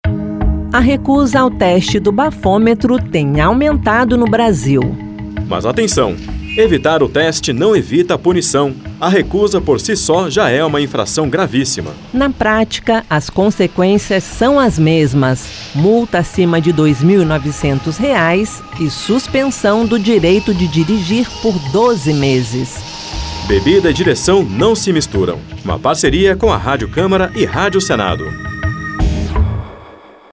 Spots e Campanhas